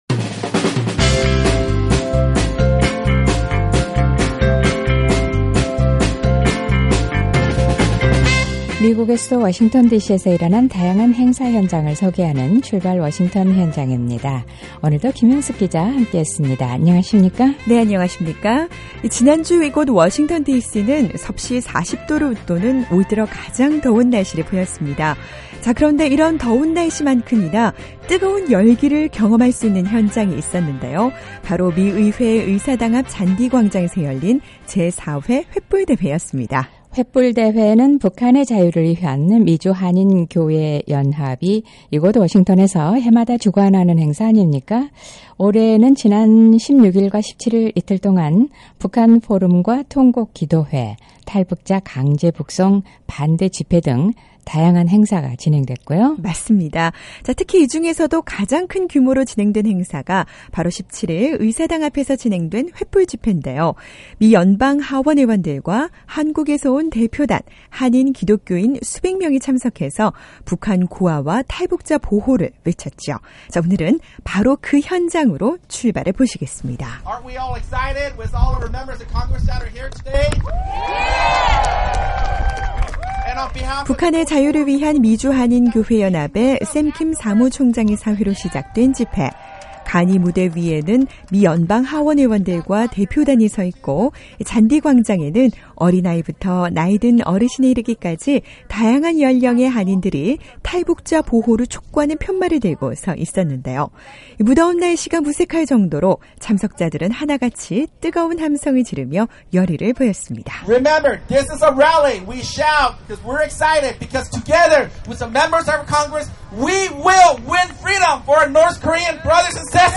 북한을 사랑하는 수 백명의 한인들이 미의회 의사당 앞 잔디광장에 모여 북한 자유를 외쳤습니다. ‘북한의 자유를 위한 미주한인교회연합’이 개최한 제4회 횃불 대회, 올해도 미연방의원들까지 참여해 성공적으로 진행이 됐는데요, 뜨거운 열기가 넘쳤던 바로 그 현장으로 출발해봅니다.